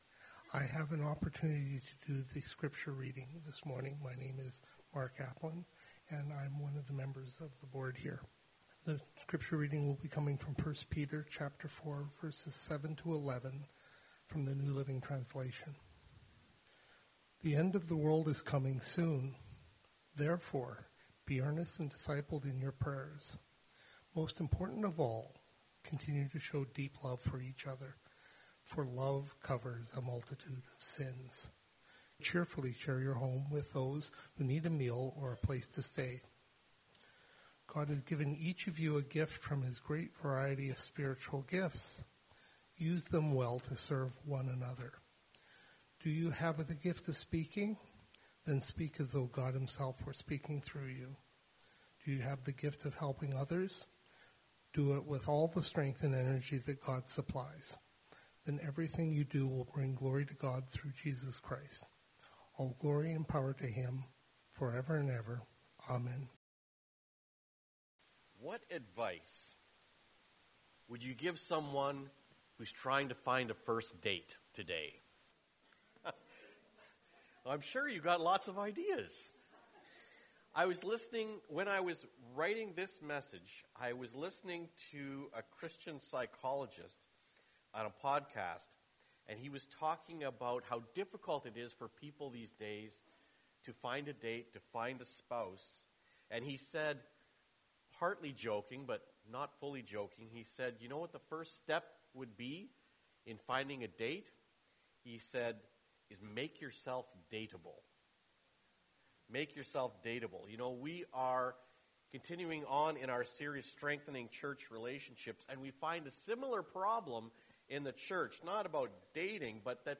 **we apologize for some audio issues with the Pastor's microphone today**